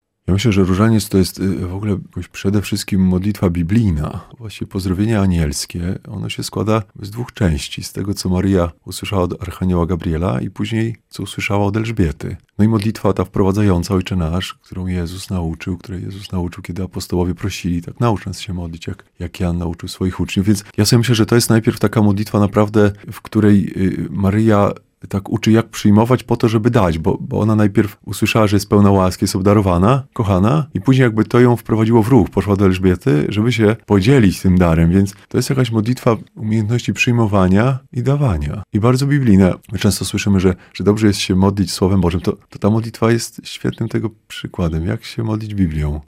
mówił na antenie Radia Nadzieja